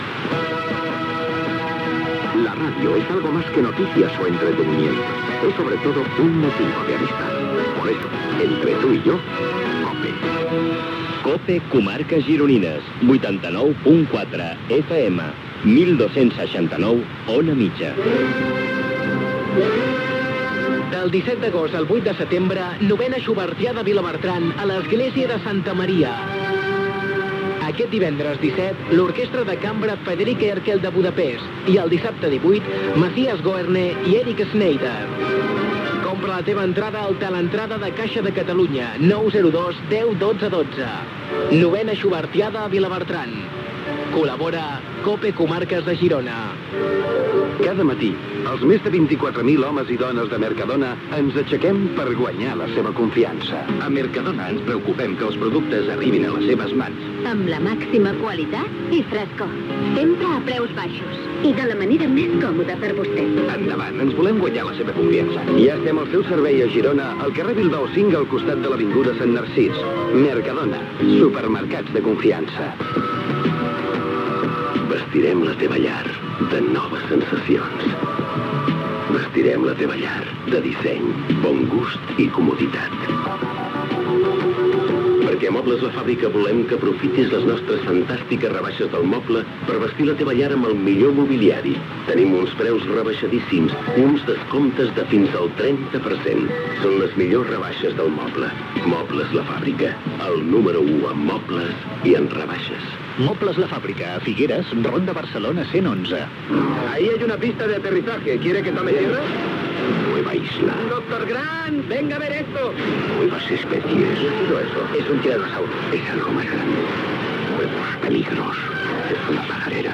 Indicatiu de la cadena i identificació de l'emissora, publicitat, promoció de "La noche de verano", publicitat, indicatiu de la cadena